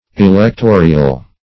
Electorial \E`lec*to"ri*al\, a.